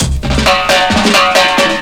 02_04_drumbreak.wav